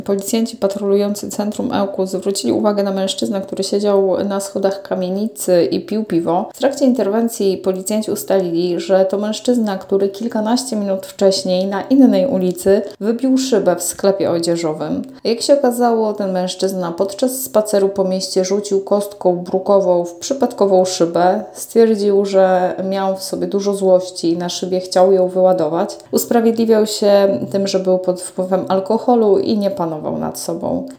Mówiła Radiu 5